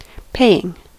Ääntäminen
Ääntäminen US Haettu sana löytyi näillä lähdekielillä: englanti Paying on sanan pay partisiipin preesens.